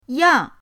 yang4.mp3